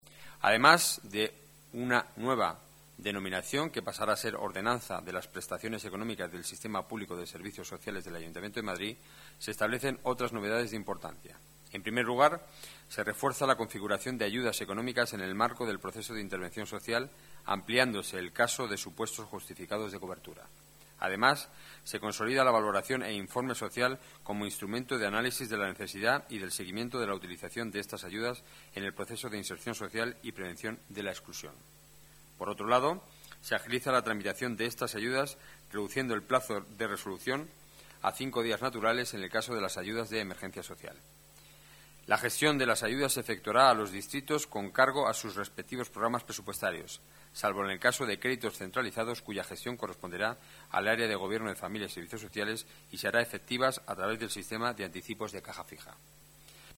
Nueva ventana:Declaraciones portavoz Gobierno municipal, Enrique Núñez: Ordenanza Servicios Sociales ayudas económicas emergencia